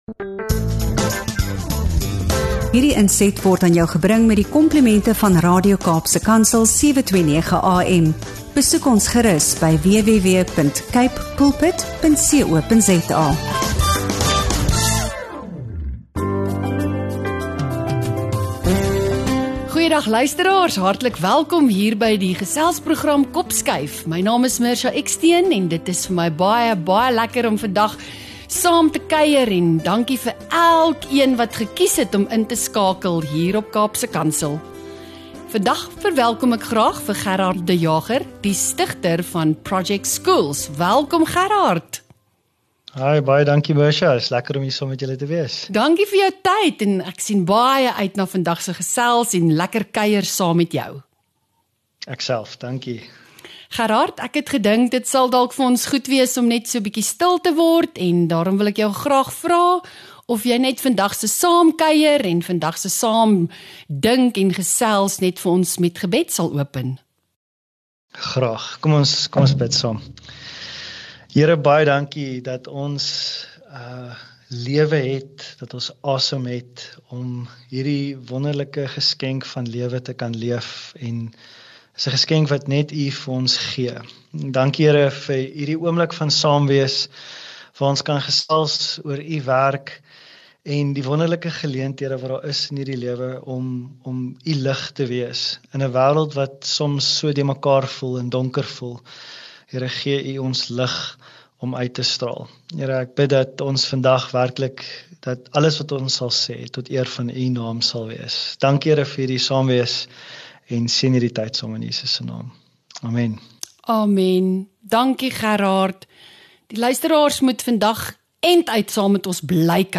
Hierdie besonderse inisiatief nooi Suid-Afrikaners uit om saam te bid vir ons land se leerders, onderwysers en skole. Luister saam vir ’n inspirerende gesprek oor opvoeding, geloof en gemeenskapsimpak.